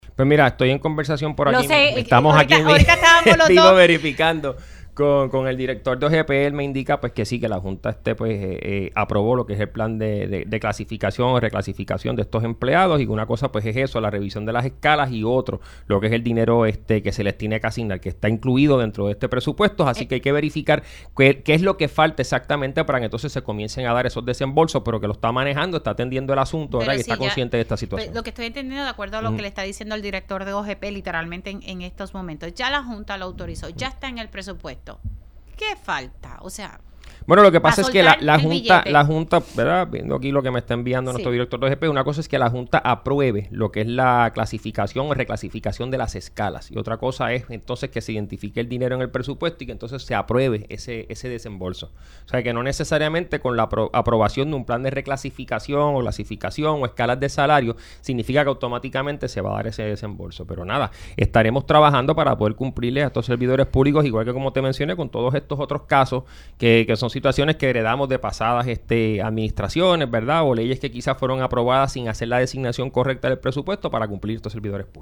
Por su parte, el secretario de Asuntos Públicos, Hiram Torres Montalvo, afirmó en este espacio que, en efecto, la Junta aprobó el Plan de Clasificación y Retribución para estos empleados.
511-HIRAM-TORRES-SEC-ASUNTOS-PUBLICOS-JSF-APROBO-PLAN-DE-RECLASIFICACION-PARA-PARAMEDICOS.mp3